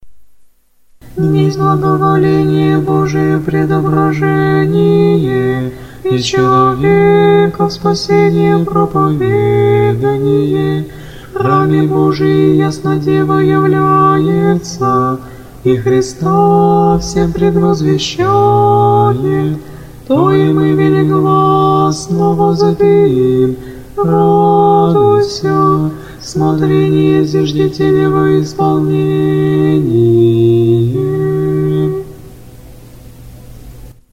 Тропарь Введения во храм Пресвятой Богородицы